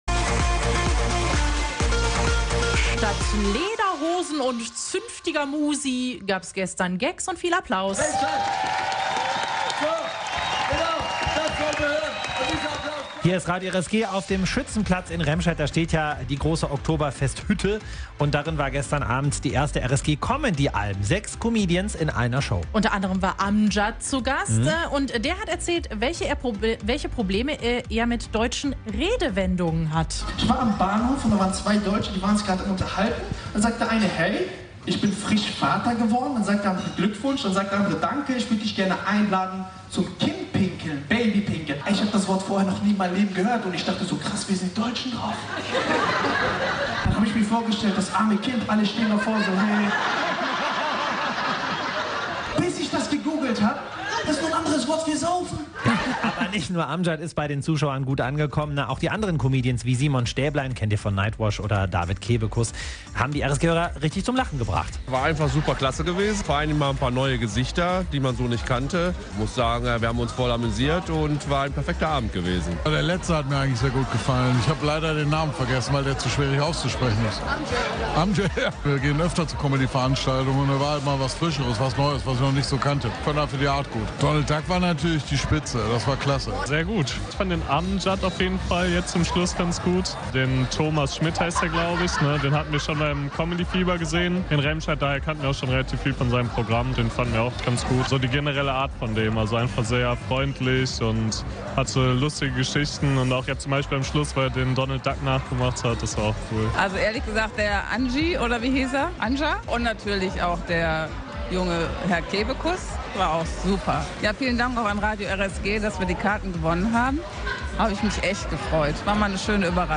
Comedy im Sixpack.
Die Remscheider Alm, das Eventzelt auf dem Schützenplatz, wurde am Dienstag zur Comedy-Alm. Gleich sechs Comedians gaben sich in der großen Holzhütte die Klinke in die Hand und begeisterten die Besucher.